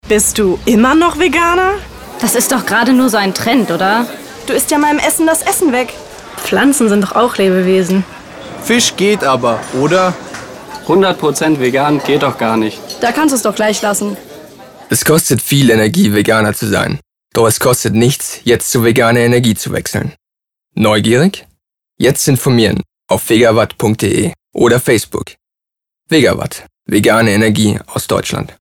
Für uns CIM-Studenten stand unter anderem die Vorlesung Werbemarkt an, bei der uns eine außergewöhnliche Aufgabe für dieses Trimester erwartete: Kreiert einen Radiospot.
accadis_Vegawatt_Funkspot.mp3